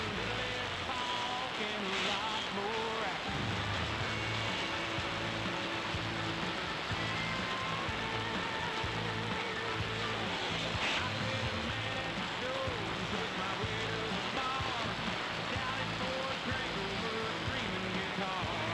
I think I may be out of luck as the day time signal is too weak for stereo and at night I was  mostly getting a spanish station.
The reception did improve marginally when I tried just now but not good enough.